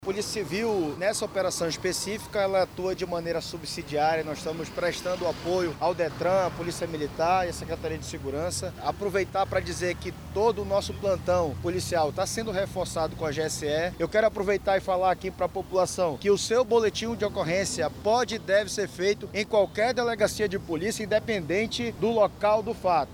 Já a Polícia Civil, trabalha em regime de plantões nas delegacias da cidade, no atendimento à população, ressalta o delegado-geral adjunto da PC/AM, Guilherme Torres.